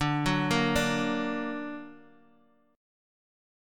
D+ Chord
Listen to D+ strummed